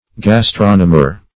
Search Result for " gastronomer" : The Collaborative International Dictionary of English v.0.48: Gastronome \Gas"tro*nome\, Gastronomer \Gas*tron"o*mer\, n. [F. gastronome, fr. Gr.